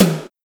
TOM06.wav